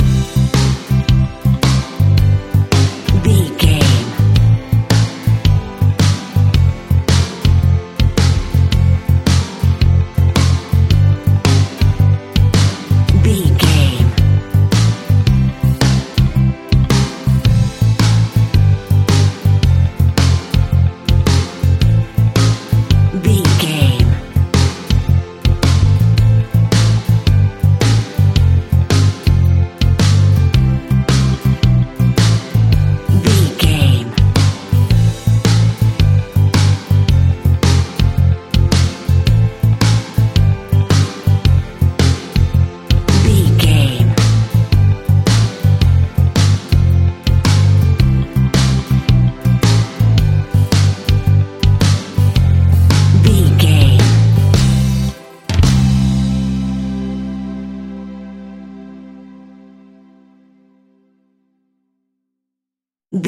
Ionian/Major
calm
happy
energetic
smooth
uplifting
electric guitar
bass guitar
drums
pop rock
indie pop
instrumentals
organ